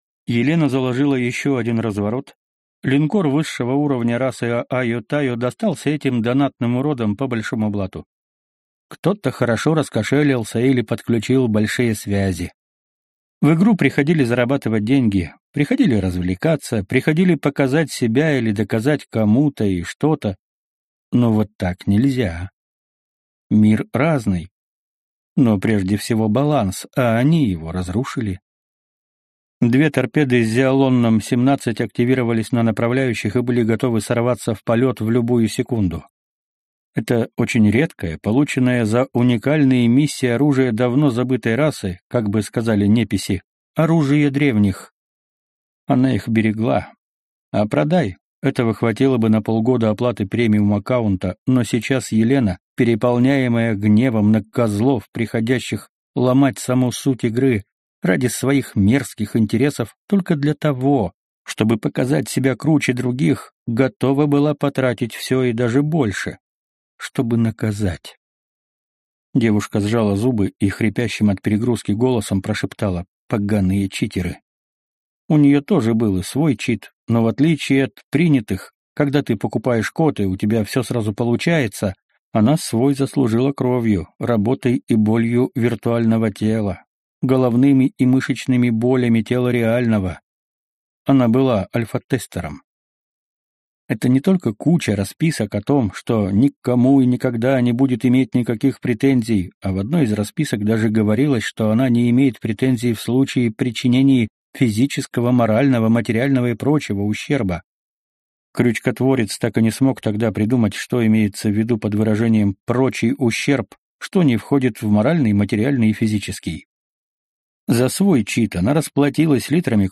Аудиокнига Трижды проклятый молот. Руконогие | Библиотека аудиокниг